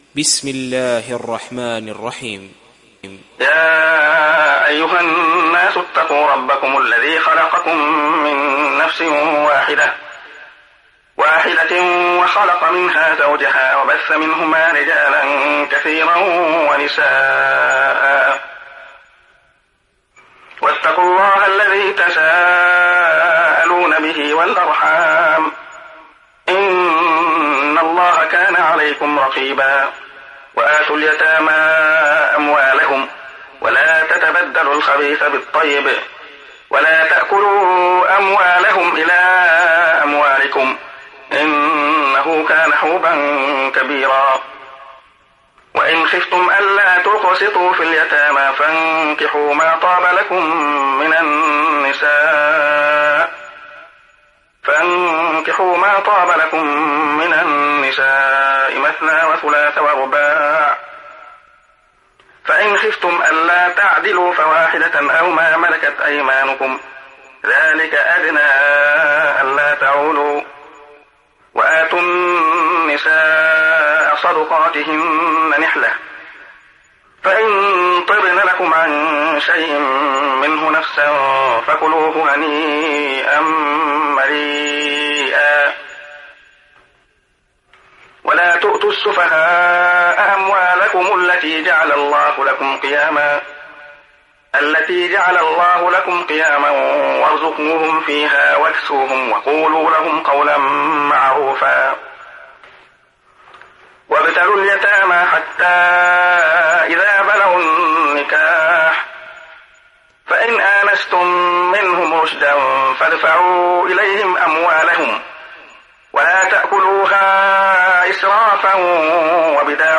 دانلود سوره النساء mp3 عبد الله خياط روایت حفص از عاصم, قرآن را دانلود کنید و گوش کن mp3 ، لینک مستقیم کامل